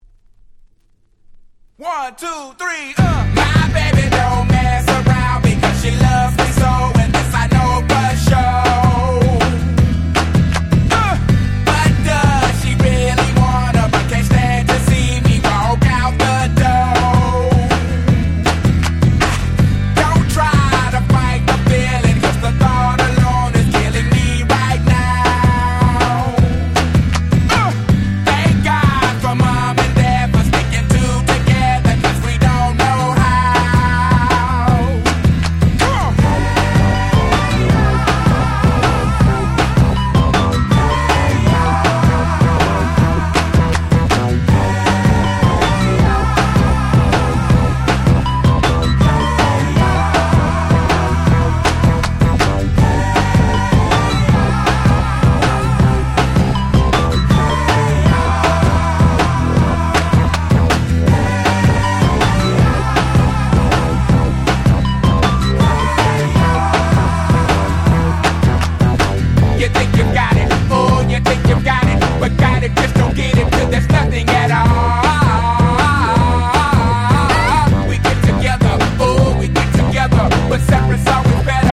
03' Super Hit Hip Hop !!
Hip Hop畑外からもクロスオーバー的な人気を誇る最強にアゲアゲな1枚！！